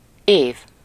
Ääntäminen
Synonyymit année printemps Ääntäminen France Tuntematon aksentti: IPA: [ɑ̃] Haettu sana löytyi näillä lähdekielillä: ranska Käännös Ääninäyte Substantiivit 1. év Suku: m .